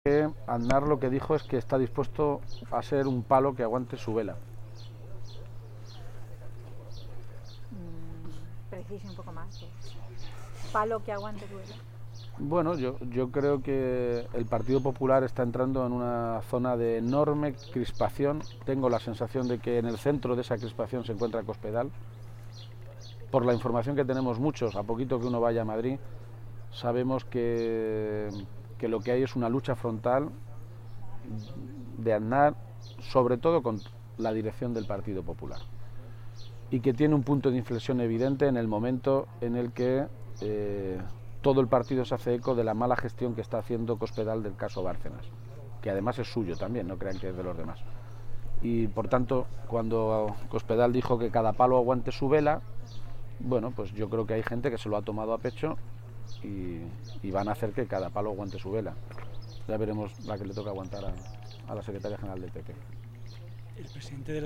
García-Page se ha pronunciado así, a preguntas de los medios de comunicación, durante su visita en Toledo a las obras del conjunto escultórico ‘Torre del Agua’ a preguntas de los periodistas sobre las críticas al Gobierno que lanzó el martes en una entrevista el expresidente del Gobierno José María Aznar.
Cortes de audio de la rueda de prensa